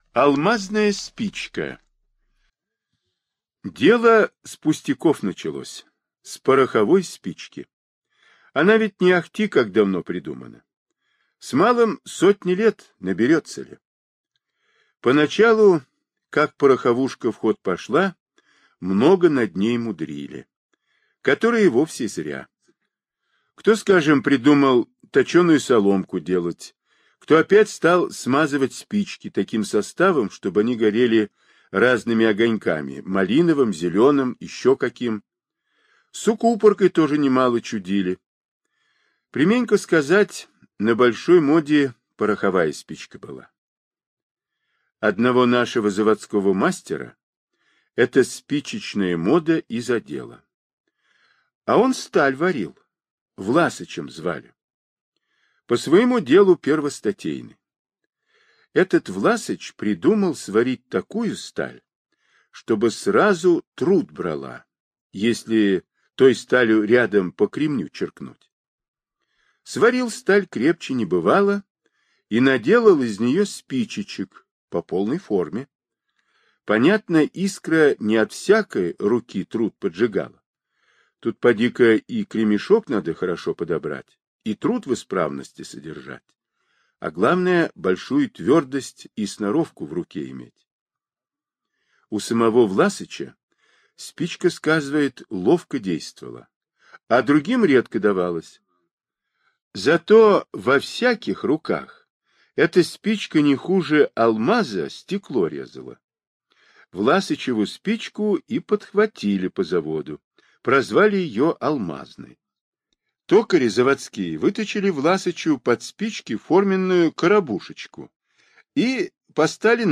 Алмазная спичка - аудиосказка Павла Бажова - слушать онлайн